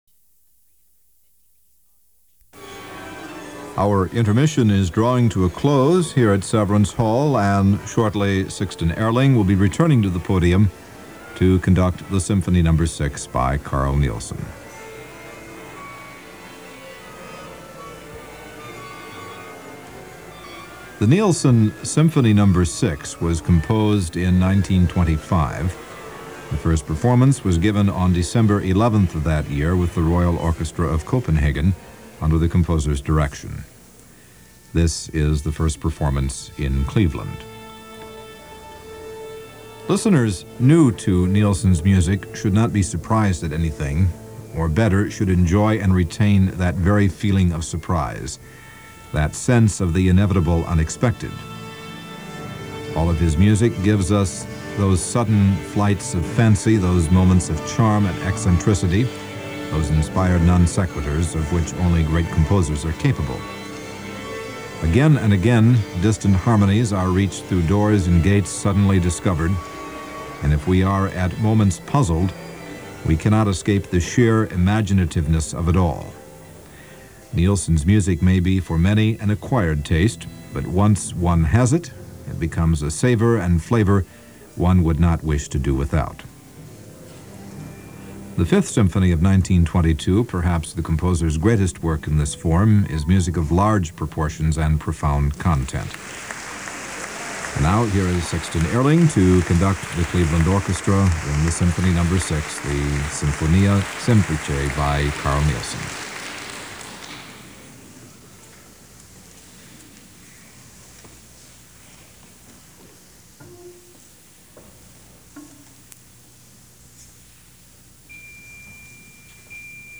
Another historic concert this week.
The concert opens with Sixten Ehrling leading the Cleveland Orchestra in the overture to Euryanthe by Carl Maria von Weber and ending the concert with Carl Nielsen ‘s Symphony Number 6 (Sinfonia Semplice). The concert was broadcast on February 23, 1978.